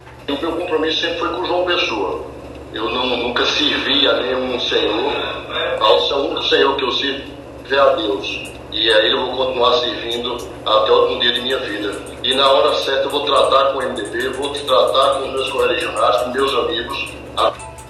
Durante entrevista ao programa Arapuan Verdade, da Rádio Arapuan FM, o vereador declarou que ainda não há definição sobre os rumos dele sobre apoios no pleito deste ano.